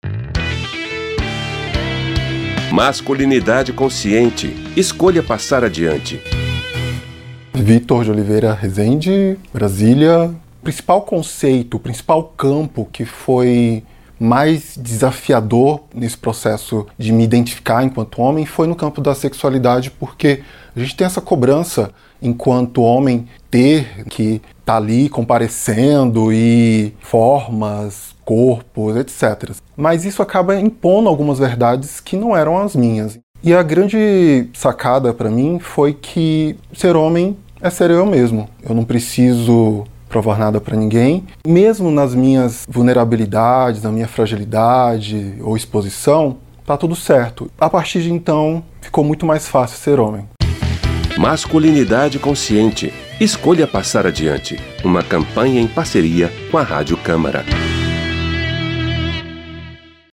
Em 13 depoimentos em áudio e cinco em vídeo com histórias reais de homens que romperam com medos e crenças a respeito da masculinidade, a Rádio Câmara lança a Campanha Masculinidade Consciente – escolha passar adiante.